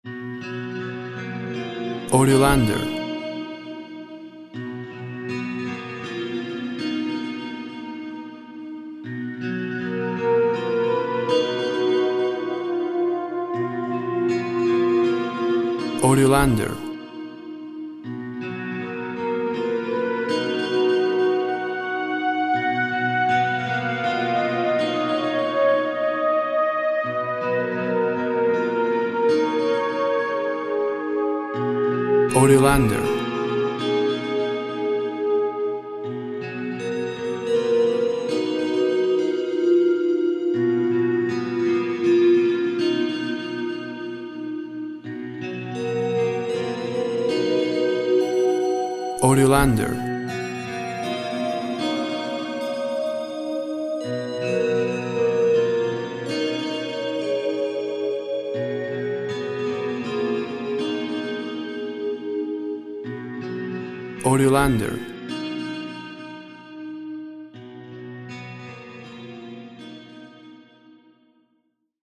Lush sounds with guitar, horn, synth and heavy reverb.
WAV Sample Rate 24-Bit Stereo, 44.1 kHz
Tempo (BPM) 68